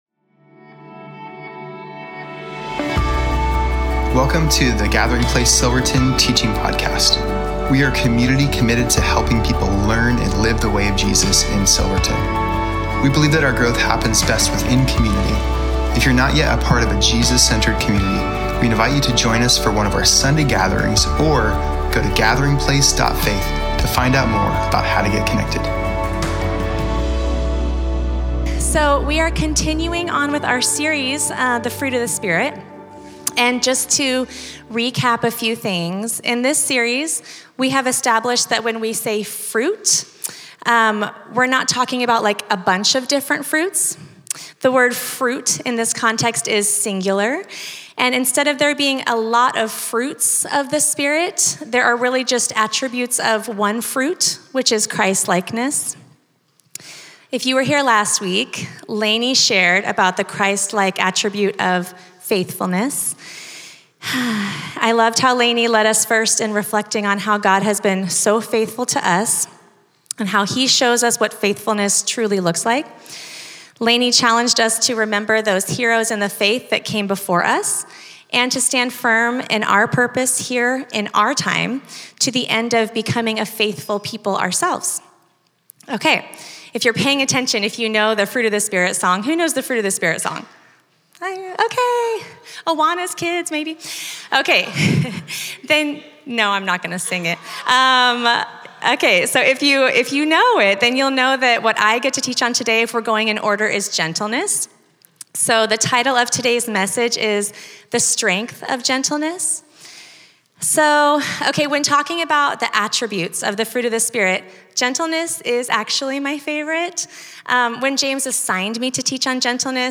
Home About Connect Events Sermons Give The Fruit of The Spirit-Part 8-Gentleness November 23, 2025